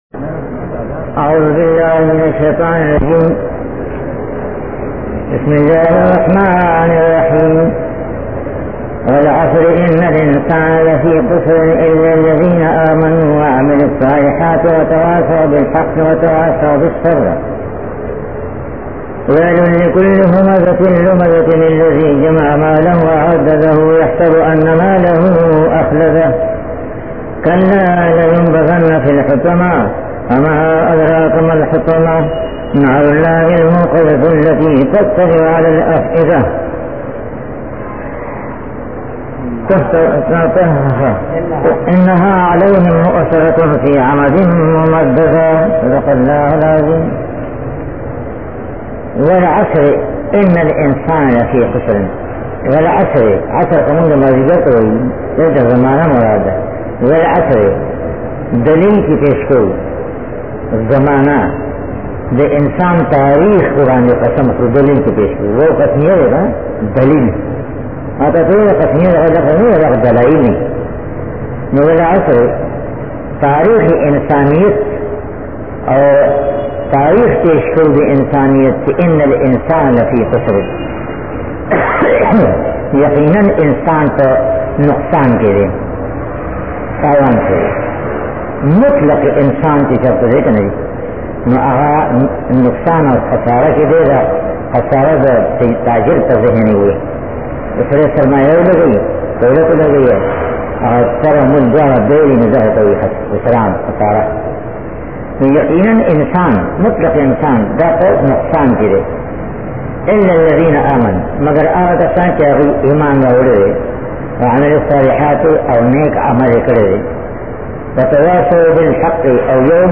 TAFSEER OF THE HOLY QURAN